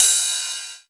068 - Ride-1.wav